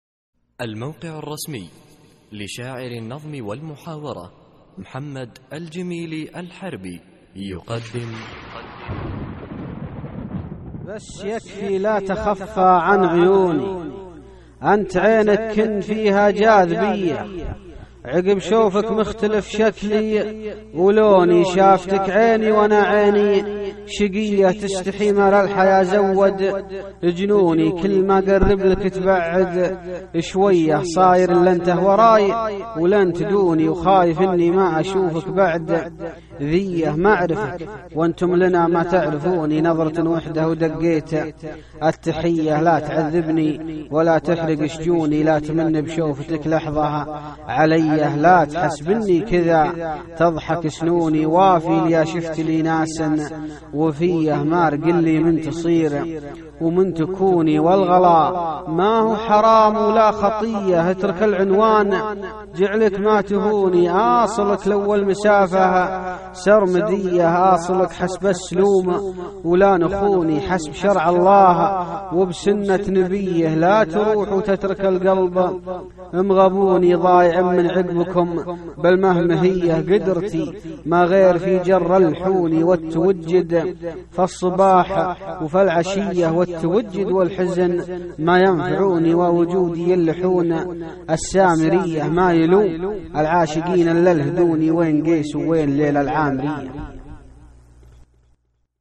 القصـائــد الصوتية
اسم القصيدة : اللحون السامرية ~ إلقاء